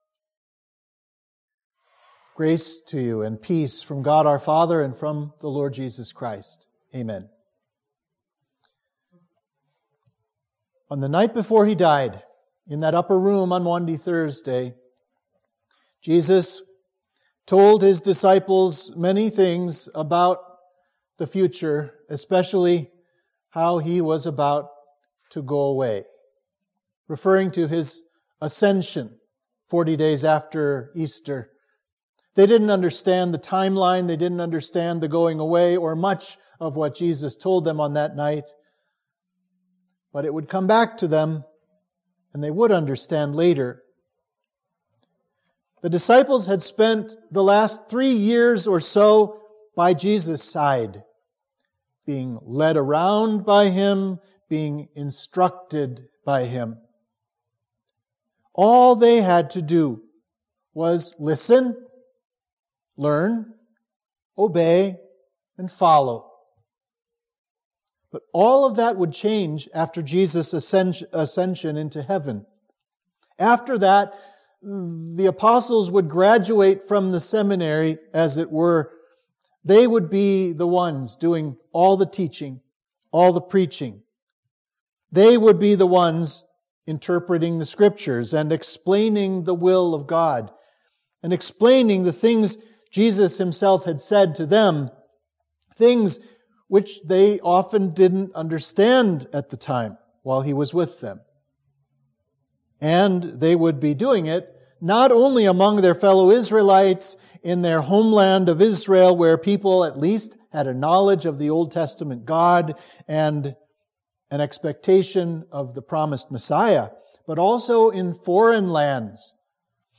Sermon for Easter 4 – Cantate